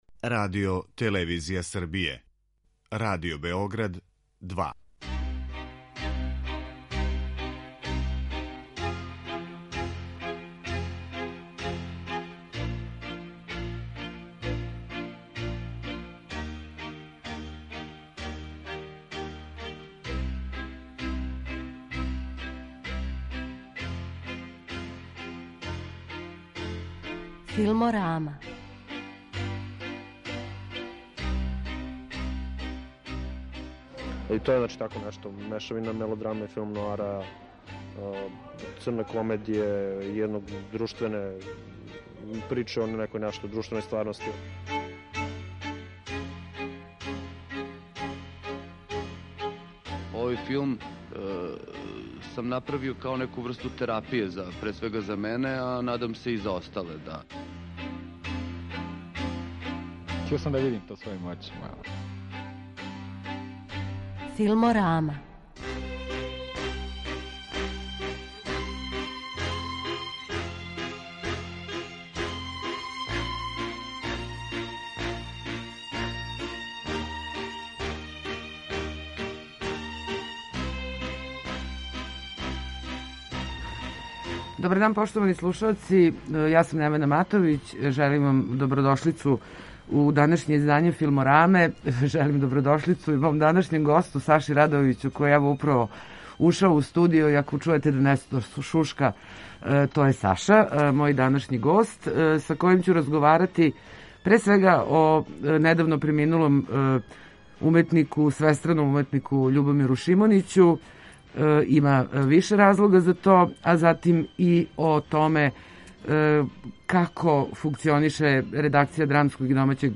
снимљен на претпремијери филма 2014. године.